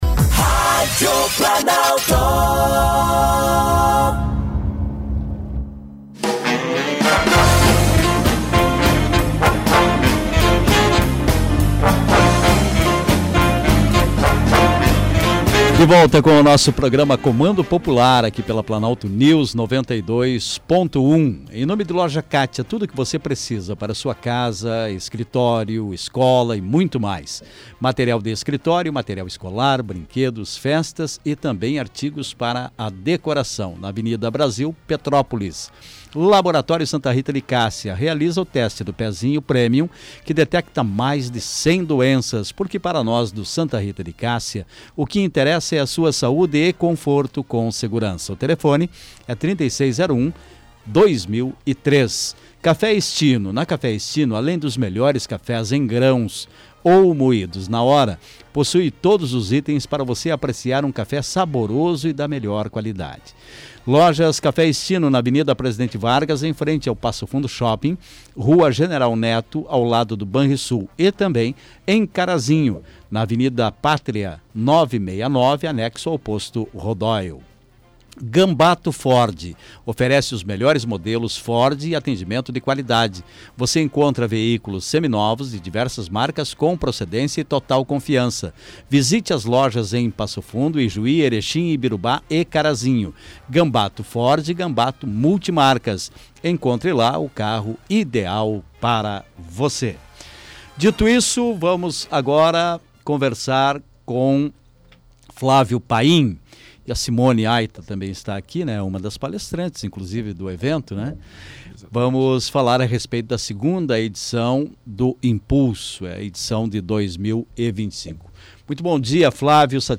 estiveram no programa Comando Popular, da Rádio Planalto News, 92.1,